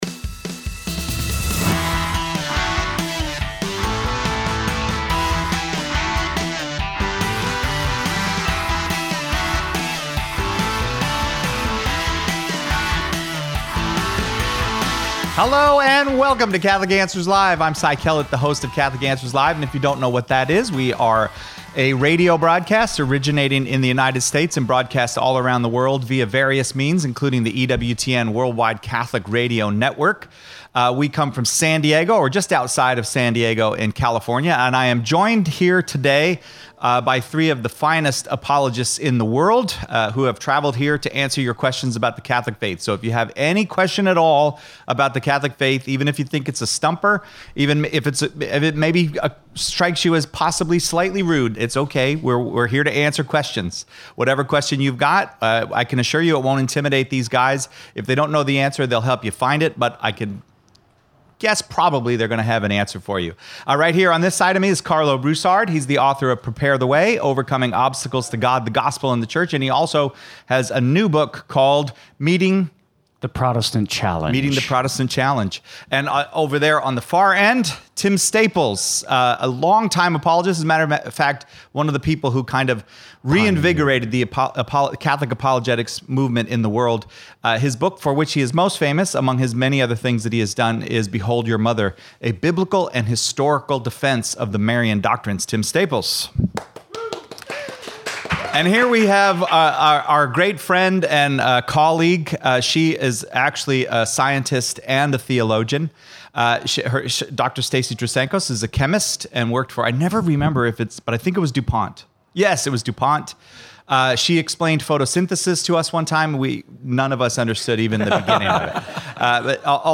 open forum from Notre Dame University in Freemantle Australia